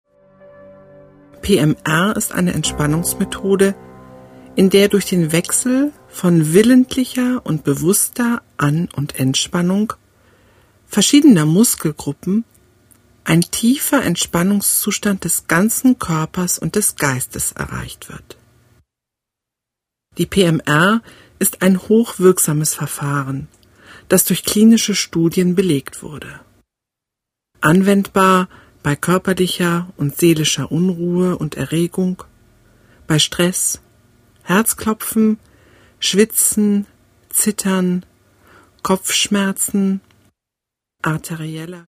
Musik: N.N.